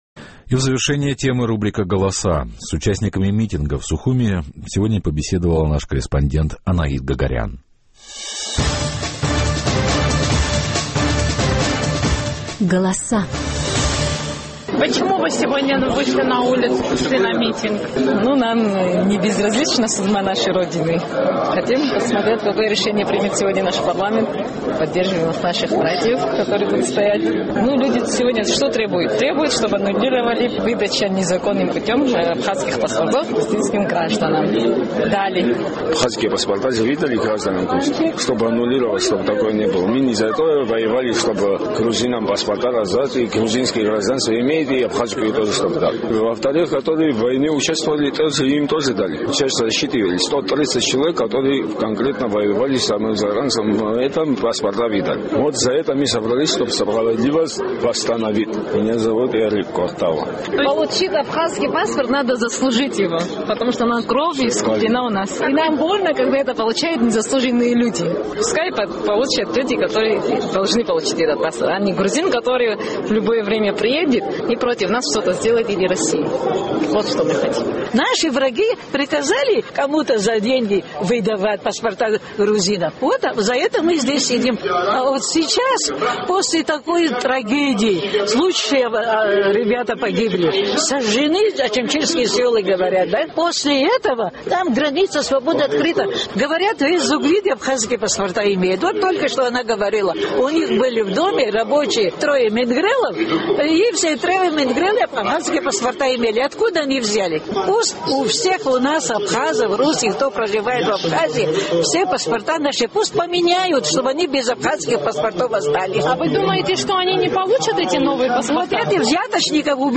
Абхазский парламент принял постановление, предусматривающее меры по устранению нарушений при выдаче паспортов. У участников митинга в столице наш корреспондент выясняла, почему они пришли на акцию.